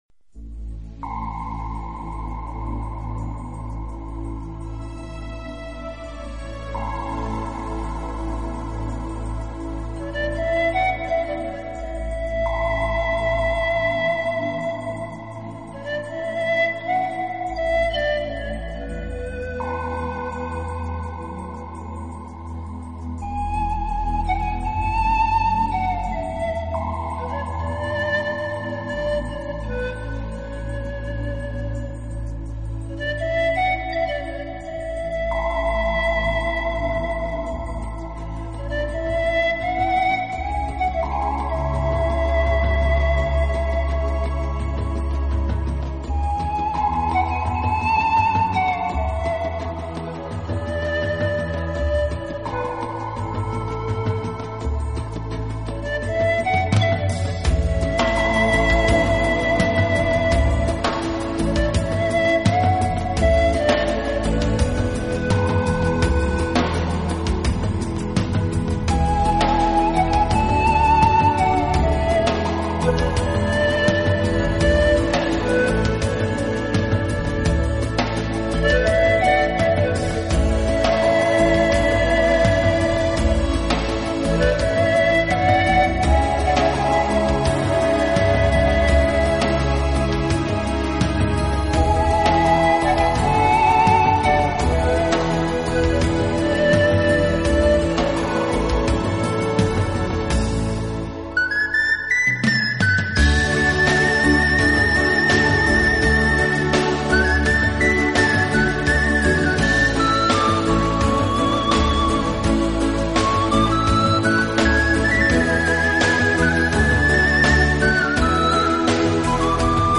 【排箫专辑】
类型: Instrumental